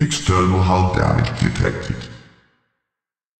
CyclopsExternalDamage.ogg